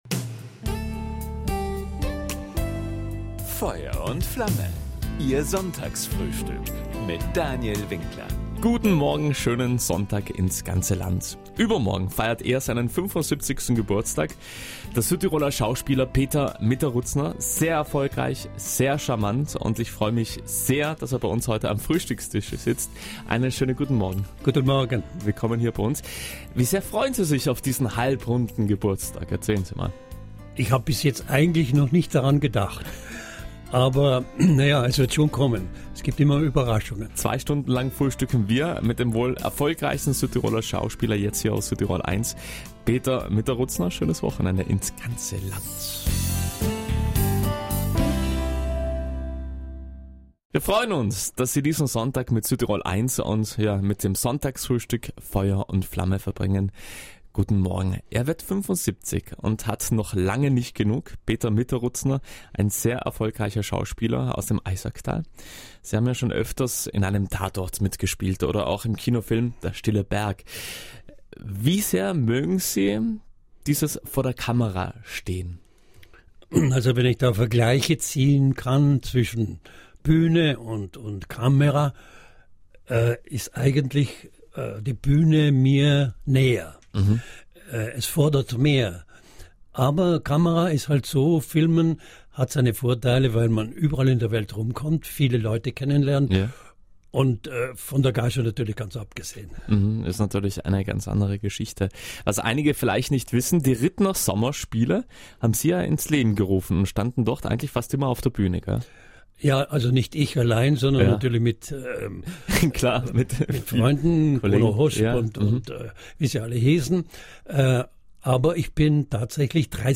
Beim Sonntagsfrühstück auf Südtirol 1, gewährt er Einblicke in sein Leben vor der Kamera und auf der Bühne.